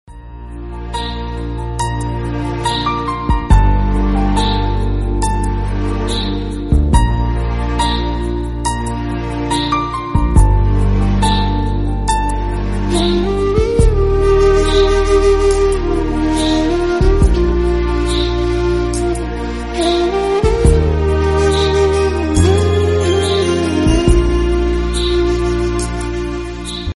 beautiful flute and piano instrumental ringtone